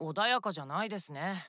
Shulk is unique in that his voice clips are all from the Japanese version of Xenoblade Chronicles regardless of language.
Super Smash Bros. for Nintendo 3DS / Wii U - One of Shulk's taunts. (Shintarō Asanuma, 2014)
Shulk_JP.oga